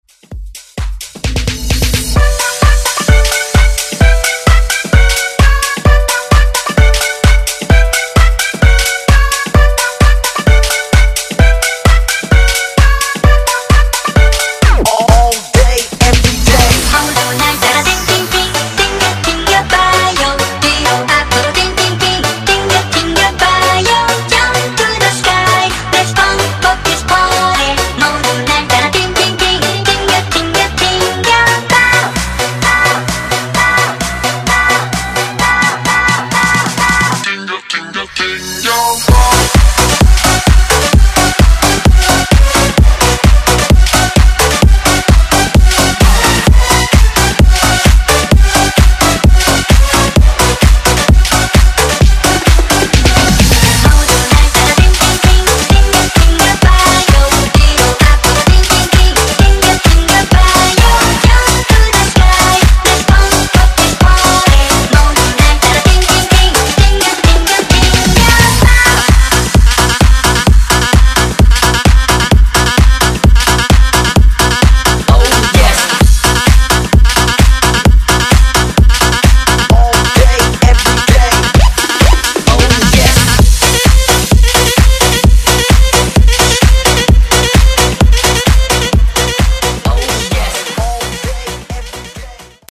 Genre: DANCE
Clean BPM: 125 Time